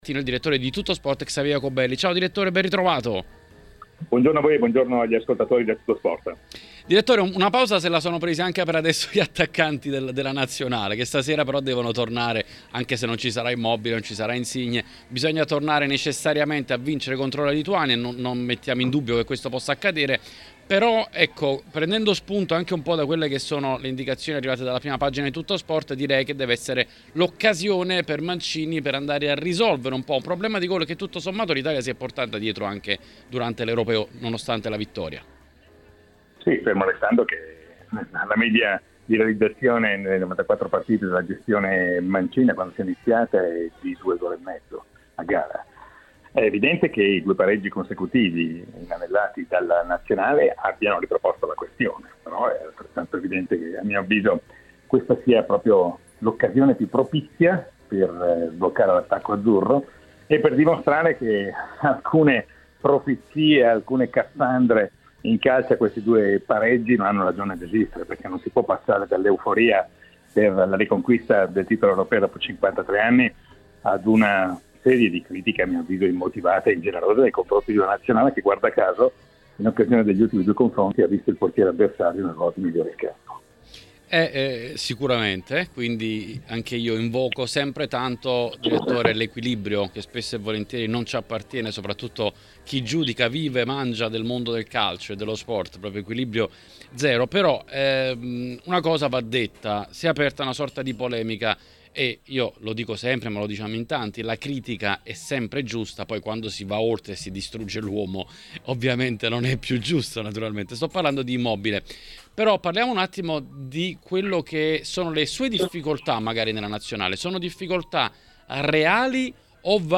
in diretta nell'editoriale del mercoledì su TMW Radio: